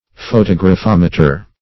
Search Result for " photographometer" : The Collaborative International Dictionary of English v.0.48: Photographometer \Pho*tog"ra*phom"e*ter\, n. [Photograph + -meter.]